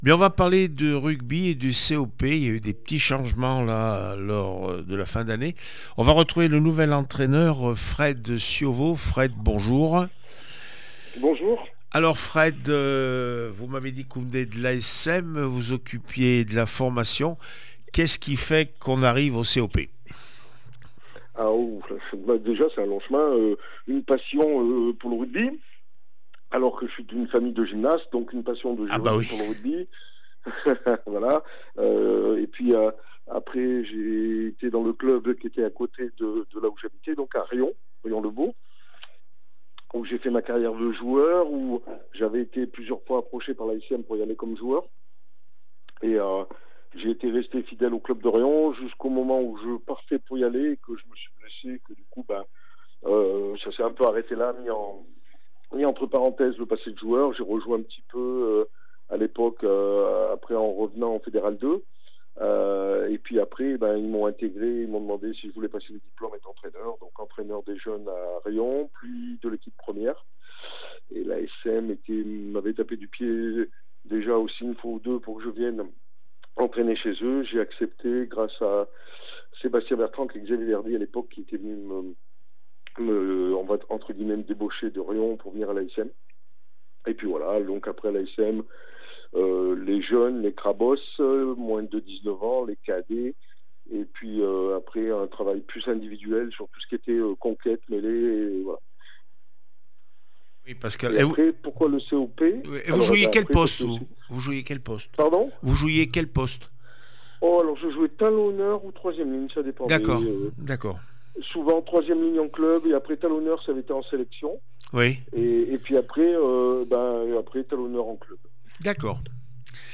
fédérale 2 rugby st Priest 46-18 cop rugby réaction après match